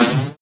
SI2 FLANGE0D.wav